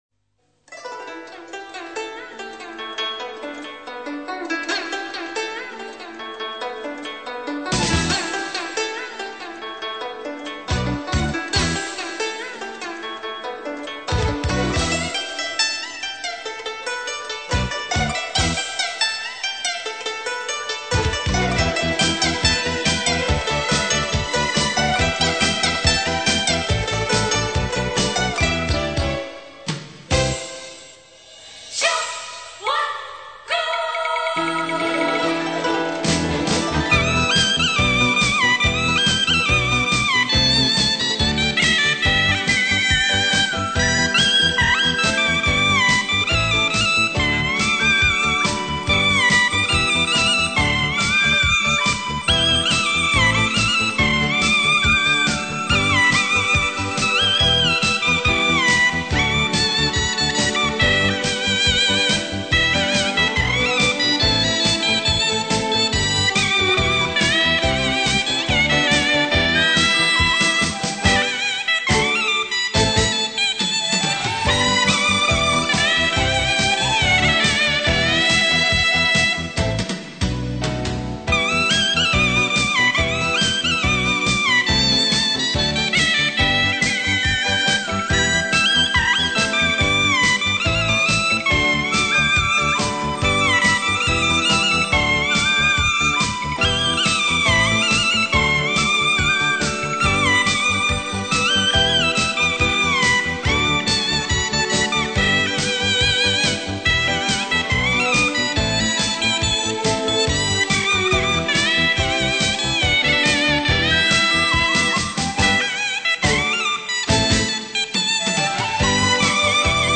把一个南方剧种沪剧的柔美曲牌用唢呐吹出具有北方音乐的豪爽、喜庆且还好听实属不易
唢呐主奏